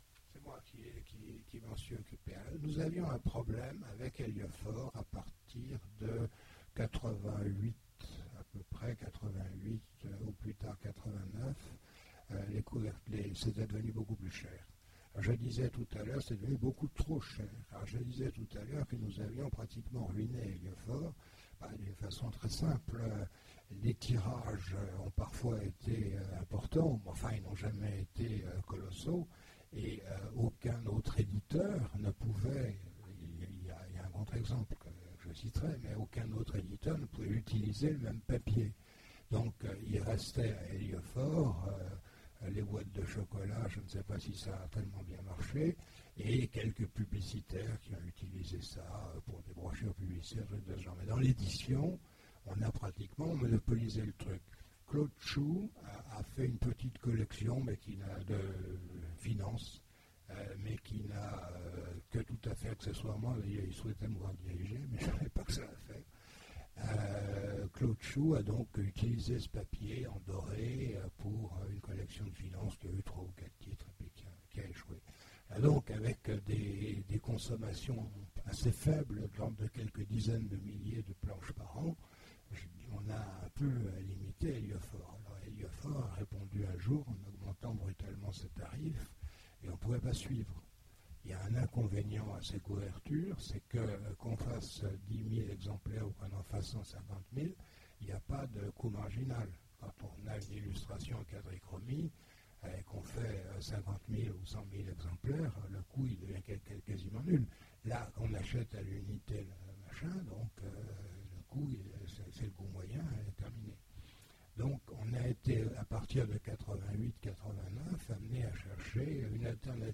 Voici l'enregistrement de la conférence avec Gérard Klein aux rencontres de l'Imaginaire de Sèvres du 12 décembre 2009 à l'occasion des 40 ans d'Ailleurs et demain.
Les questions du public: